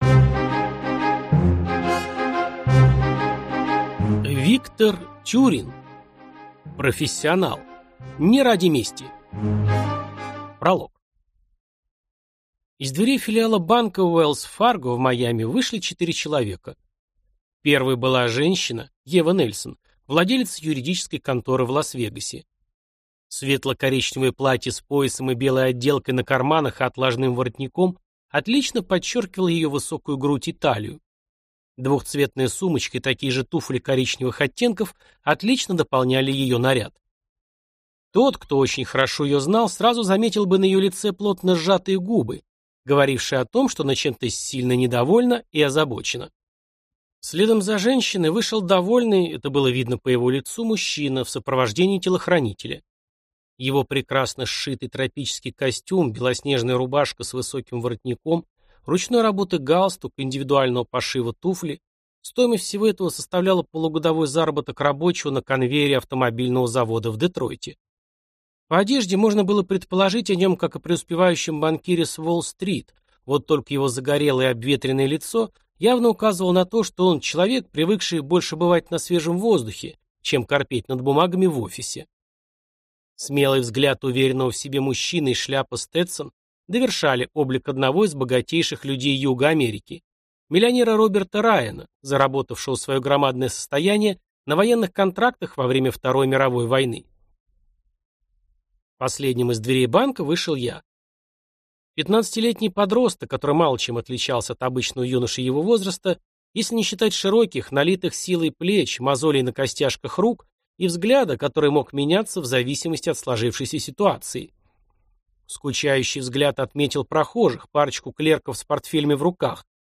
Аудиокнига Профессионал. Не ради мести | Библиотека аудиокниг